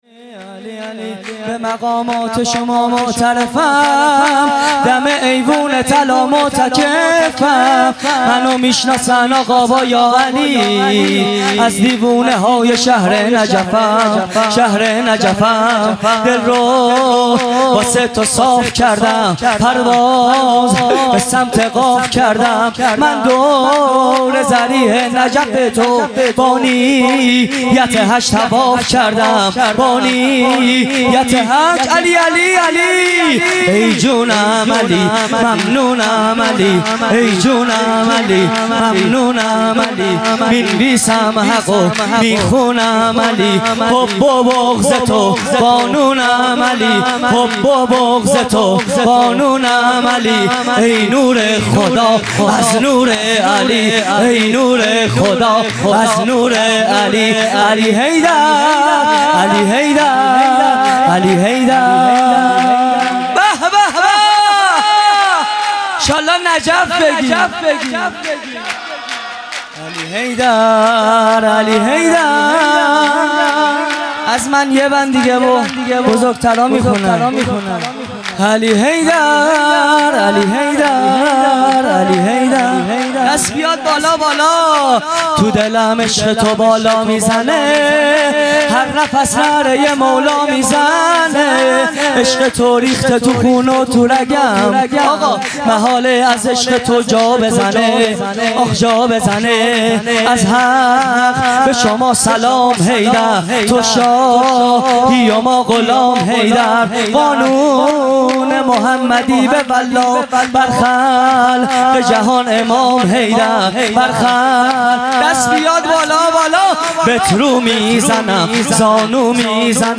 0 0 سرود | به مقامات شما معترفم مداح
جشن عید غدیر